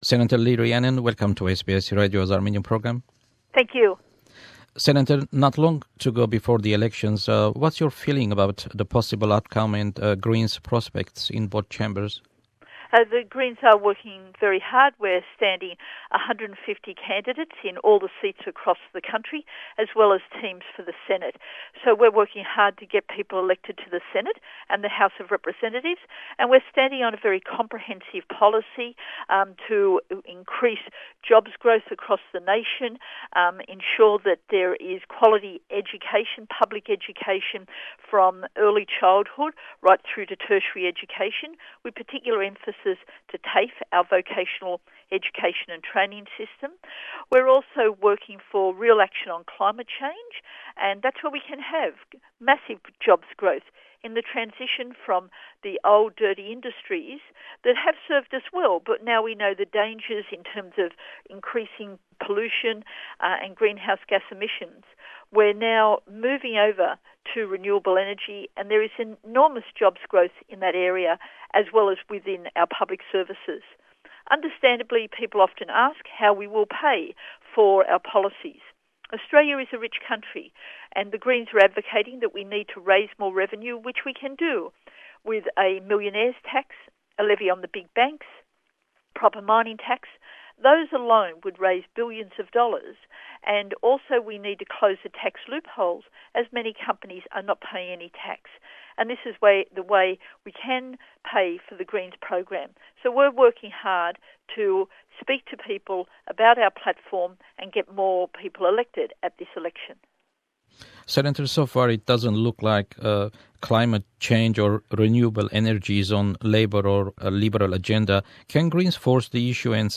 An interview with Greens NSW Senator Lee Rhiannon about federal election, policies on environment, renewable energy, Medicare, asylum seekers and the cruel trade of live animal export.